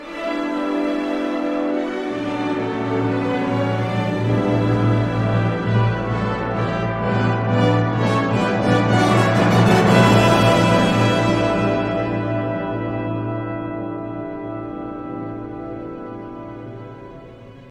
Hér má heyra hljóðritun frá fyrstu tónleikum Sinfóníuhljómsveitar Íslands, með ávarpsorðum dr. Páls Ísólfssonar, sem haldnir voru í Austurbæjarbíói 9. mars árið 1950.
fyrir flautu, óbó, klarinettu, fagott og horn